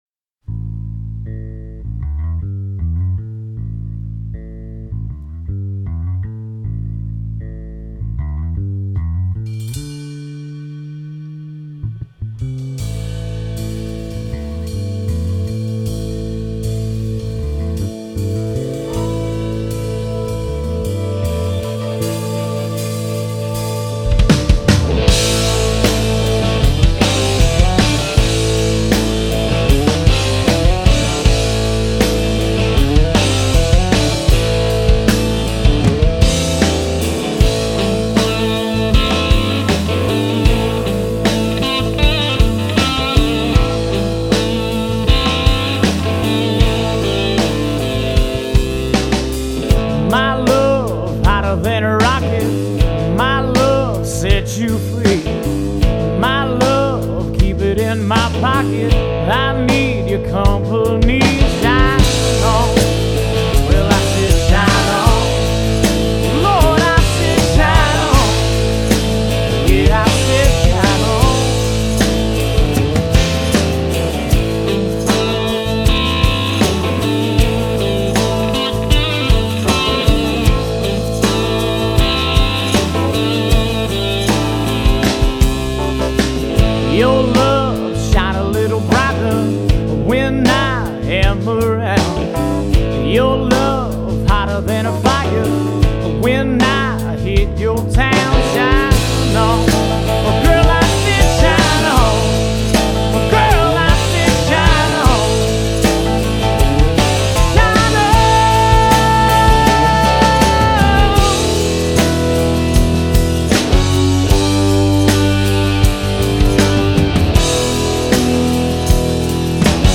Genre: Blues / Southern Rock
Vocals, Guitar, Harmonica